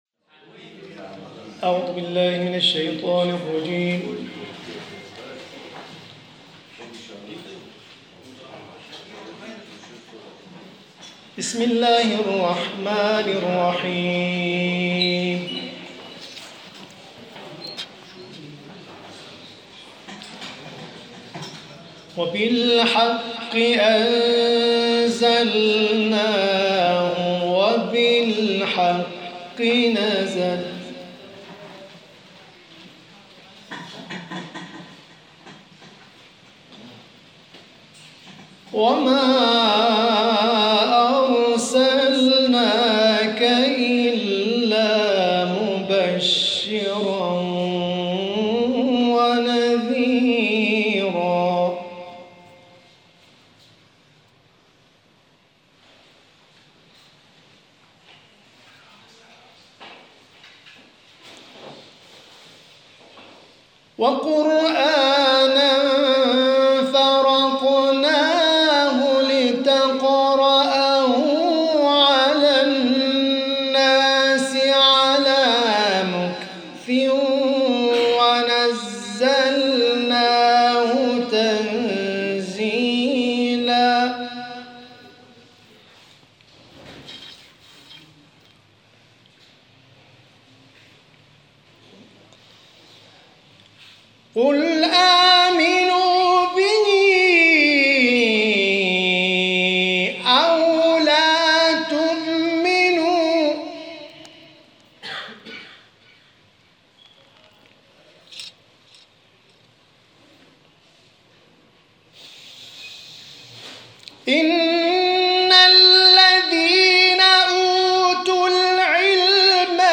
تلاوت آیات 105 تا 111 سوره مبارکه اسراء و سوره مبارکه اخلاص